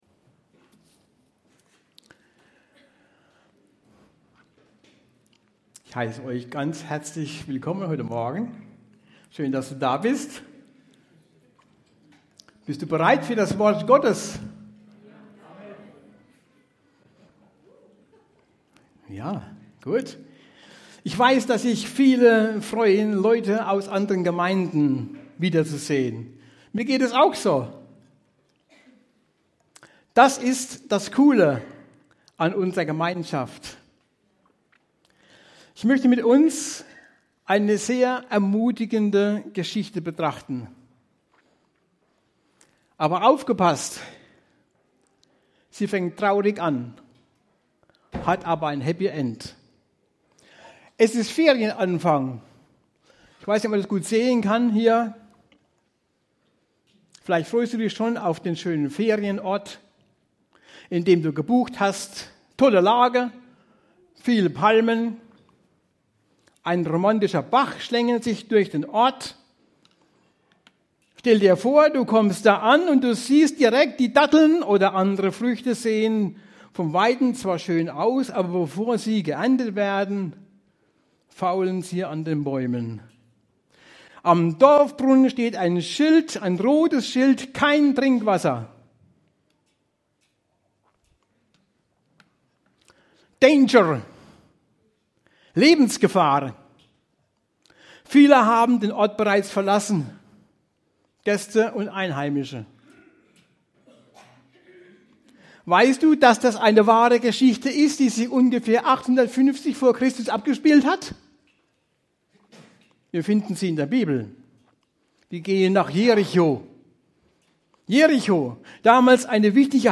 Allianz-Sommerfest 2024
Predigt-Sommerfest-2024.mp3